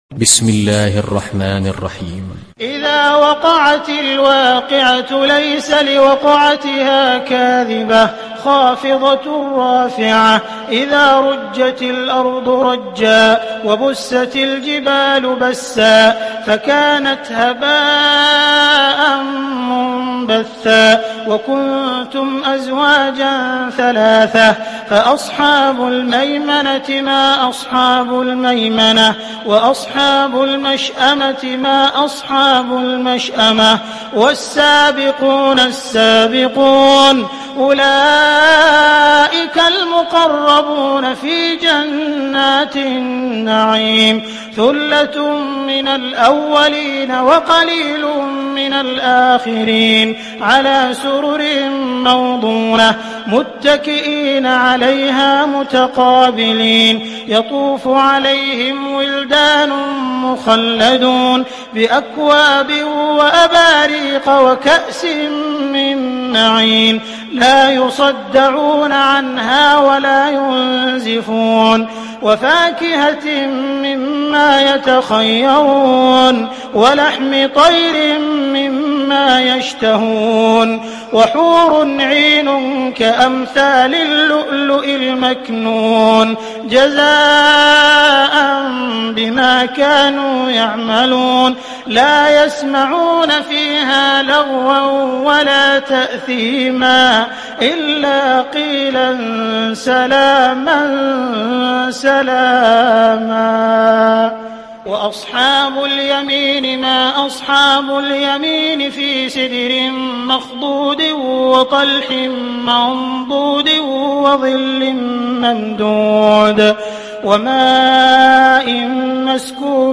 Surat Al Waqiah Download mp3 Abdul Rahman Al Sudais Riwayat Hafs dari Asim, Download Quran dan mendengarkan mp3 tautan langsung penuh